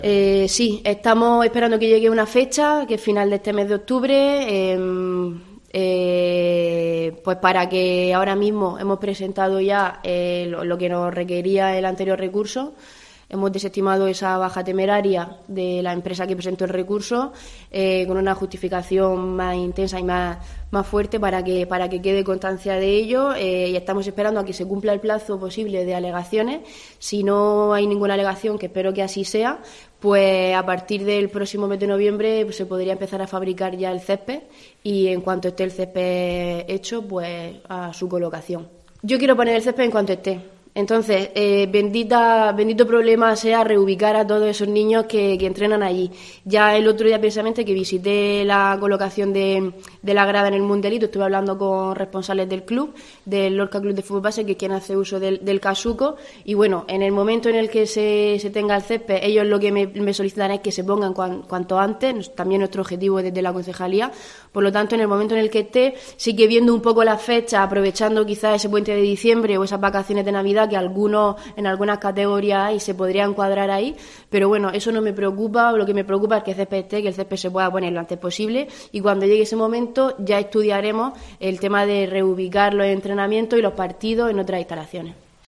Iréne Jódar edil de Deportes sobre el Juan Casuco